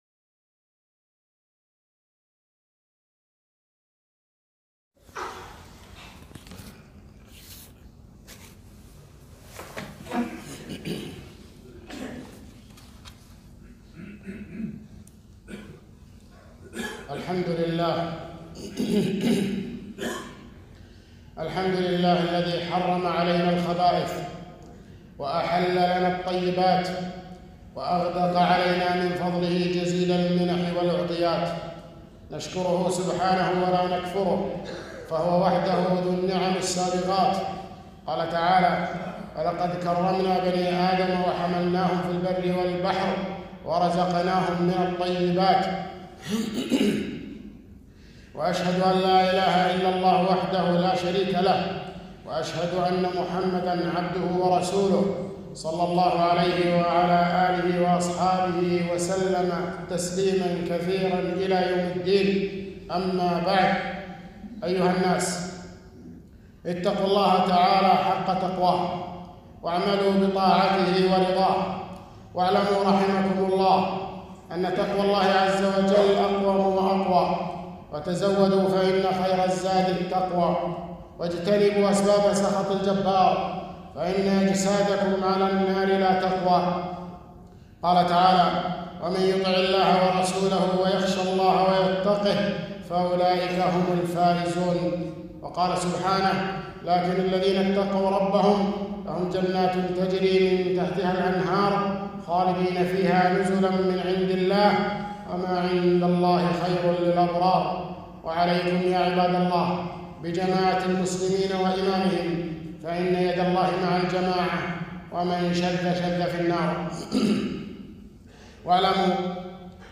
خطبة - الروائح الطيب منها والخبيث